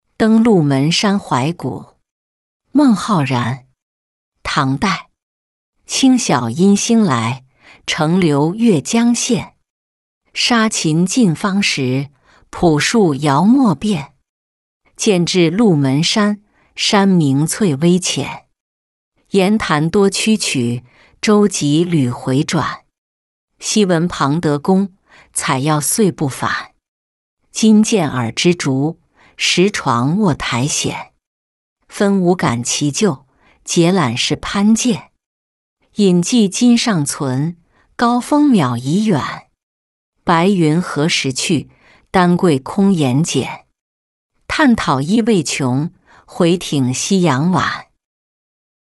登鹿门山怀古-音频朗读